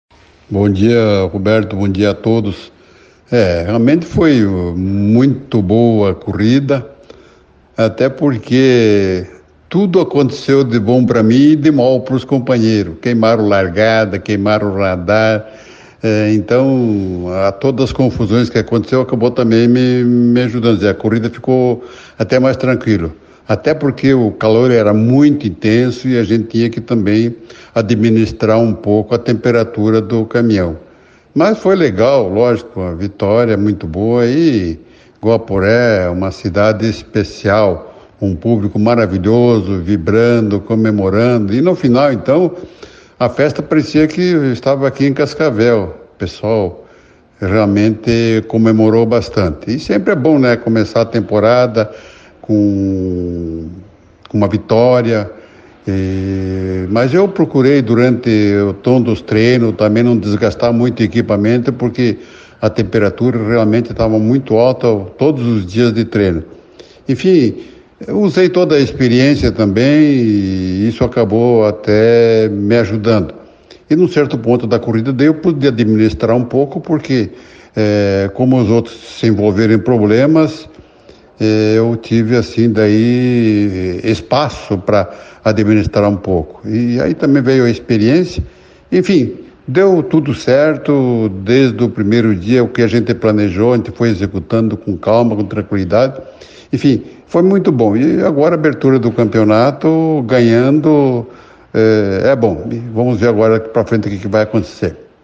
Em entrevista à CBN Cascavel nesta segunda-feira (13) Pedro Muffato, aos 82 anos, detalhou a vitória conquistada na categoria GT Truck na etapa de abertura do Campeonato Brasileiro de Fórmula Truck, disputada neste domingo (12), no Autódromo de Guaporé, no Rio Grande do Sul.